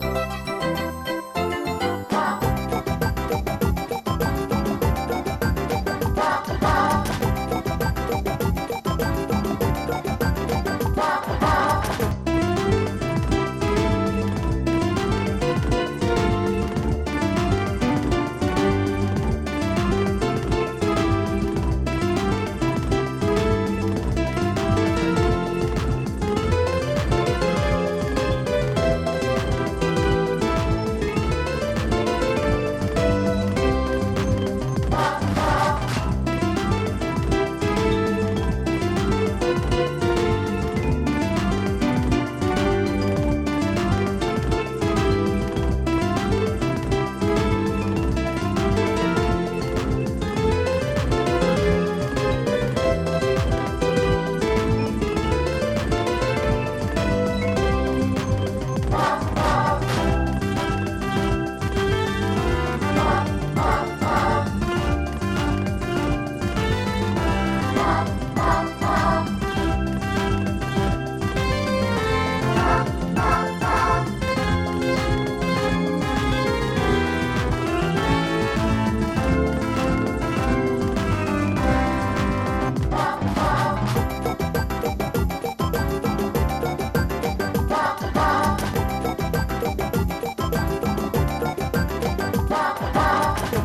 (It's a trimmed up mashup so if it sucks, that's why)